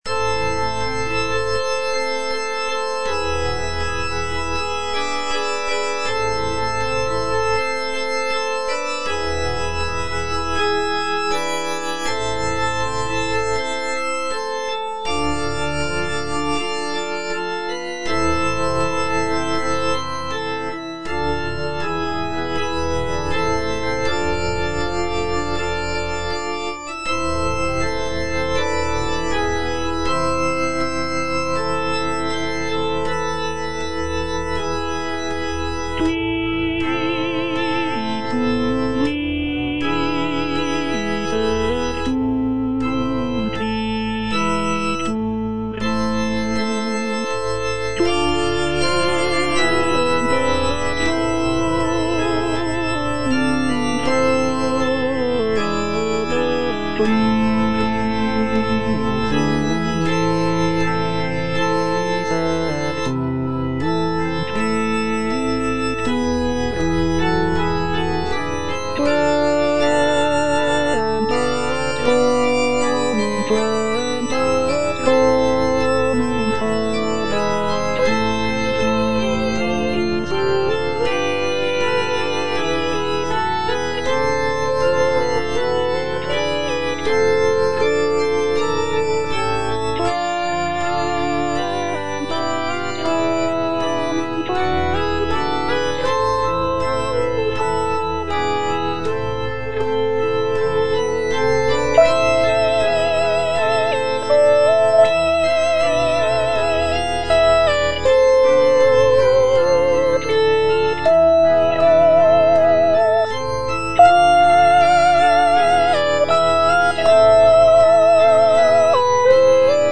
Soprano (Voice with metronome) Ads stop
is a sacred choral work rooted in his Christian faith.